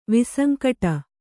♪ visankaṭa